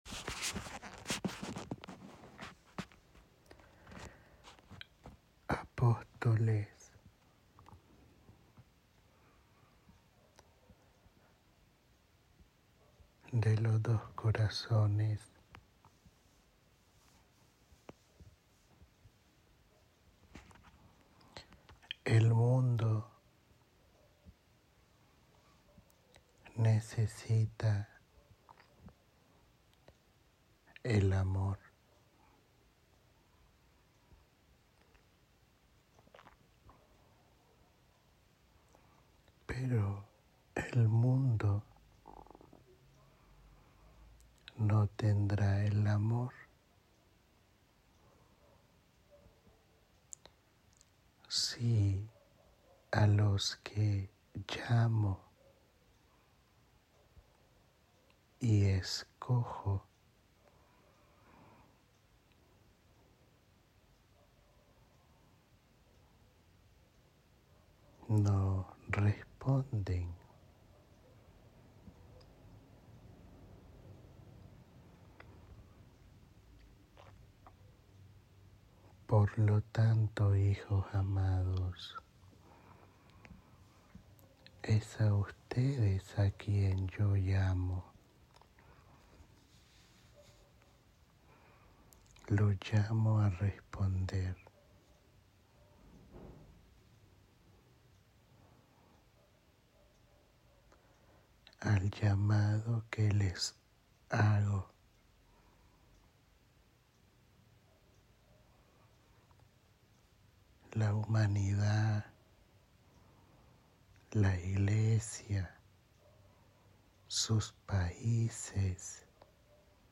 (No Primeiro Retiro Eucarístico-Mariano do Apostolado, “Nascidos de Maria” – Panamá – 22 a 24 novembro 2024)